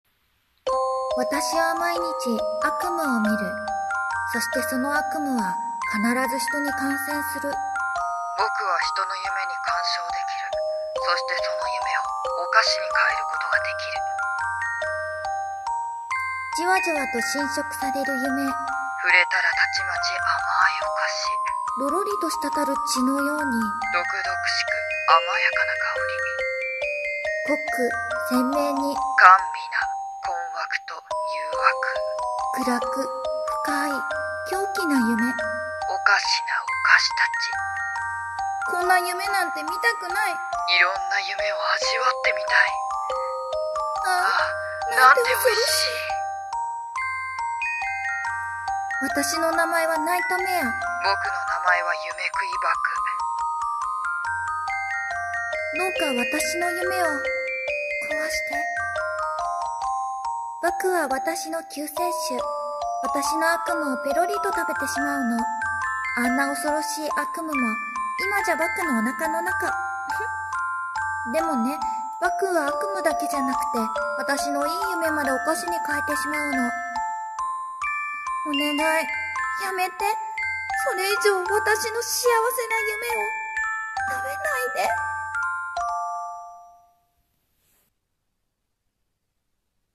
【声劇】ナイトメアのお菓子な悪夢